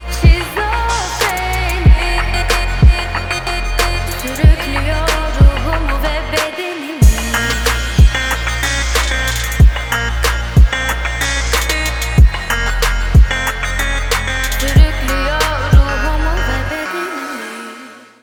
• Качество: 320, Stereo
Для грустных вообще супер будет.